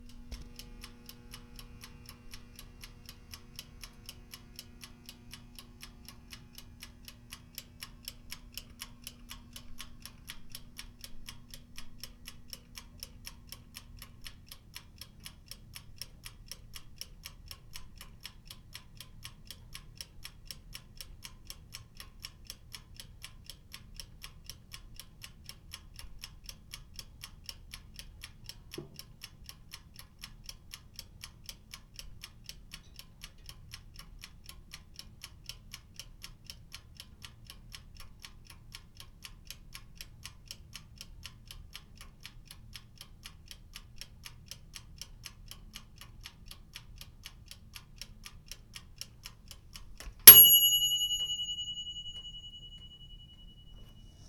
Toaster oven timer and ding
alarm ding kitchen time timer toaster toaster-oven sound effect free sound royalty free Sound Effects